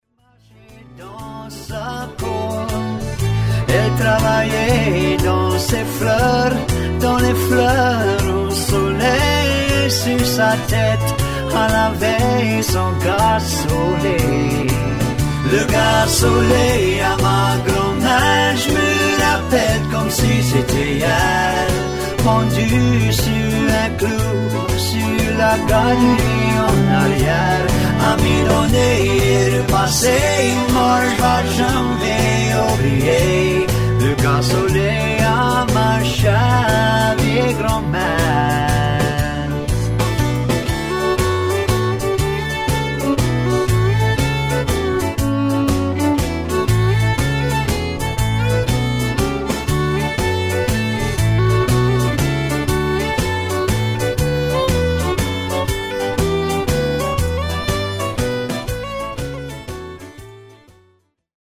Accordion, Vocals and Harmony Vocals, Bass Guitar
Fiddle on 2
Drums on all